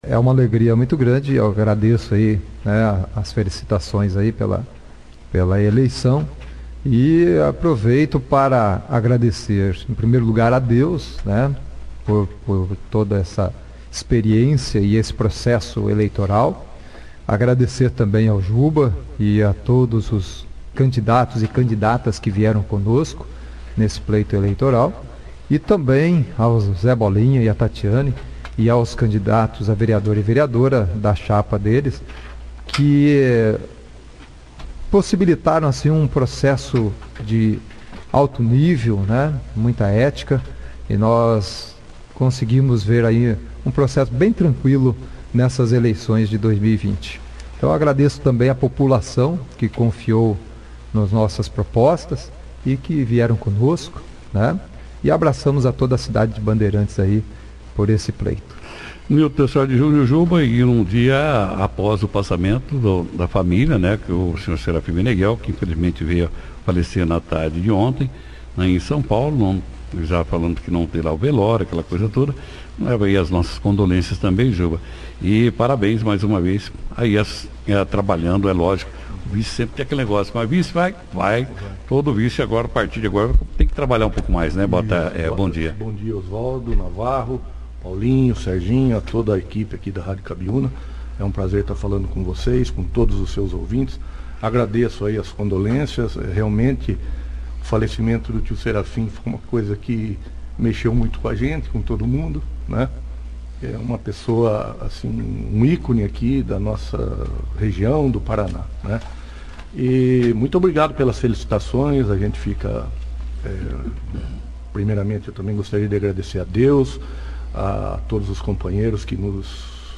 Eleitos em Bandeirantes no último dia 15 de novembro, para exercerem o cago de Prefeito Jaelson Ramalho Matta e vice-prefeito Nilton De Sordi Jr. (Juba),(foto), participaram ao vivo da 2ª edição do jornal Operação Cidade desta segunda-feira, 23/11, agradeceram os votos recebidos, falaram do andamento da transição e responderam sobre várias questões que deverão enfrentar na administração do município a partir de 01 de janeiro de 2021.